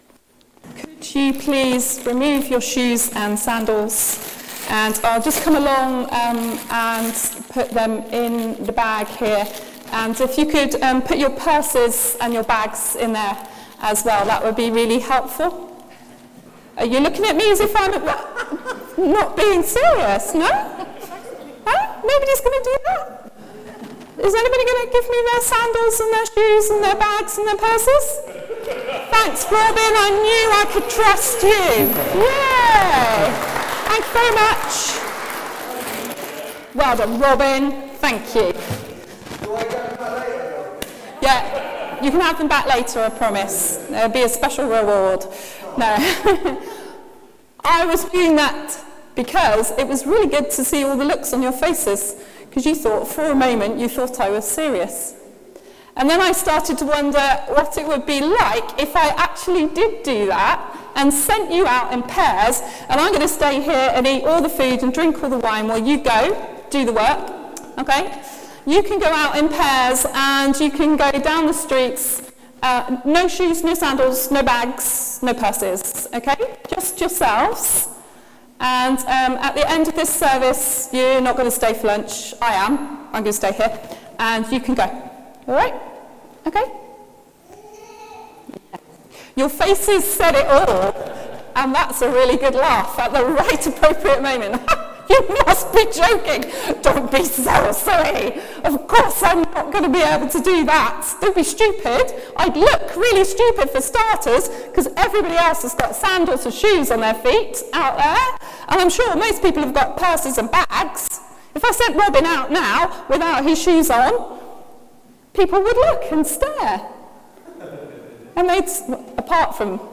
Sermon: Jesus sends out the seventy two | St Paul + St Stephen Gloucester